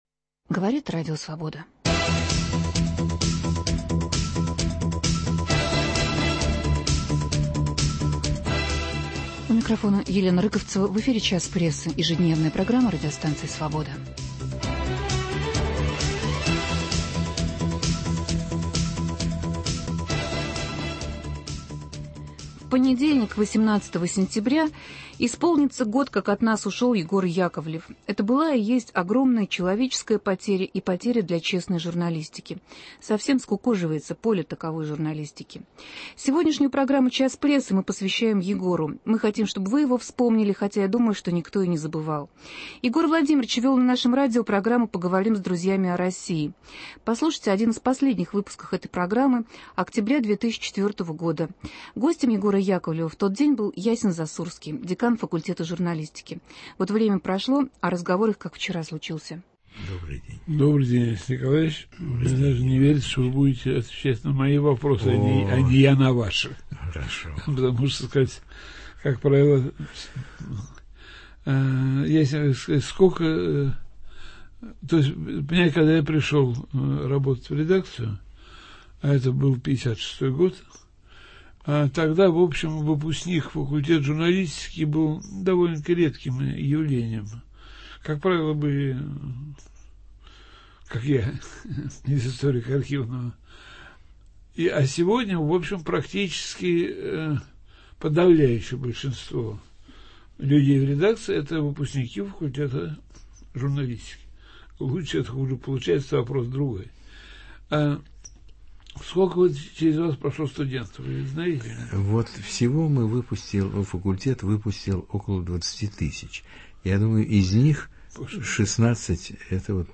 Вы услышите запись беседы Егора Владимировича с деканом факультета журналистики МГУ Ясеном Засурским. То был разговор о судьбах современной прессы.